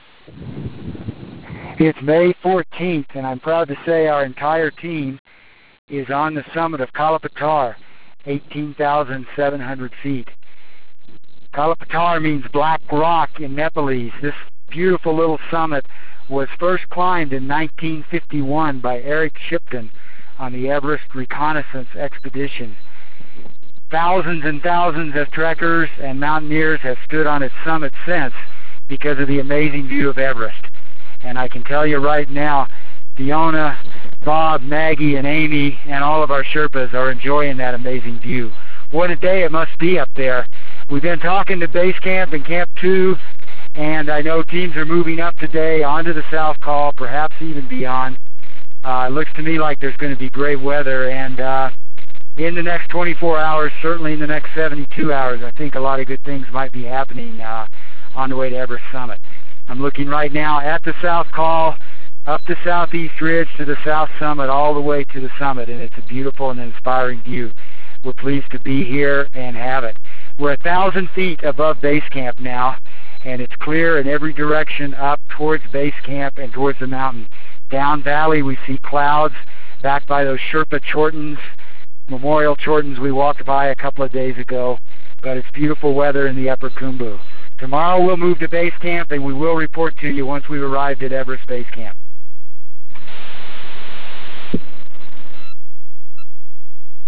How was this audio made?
May 14 - Reaching the Summit of Kala Patar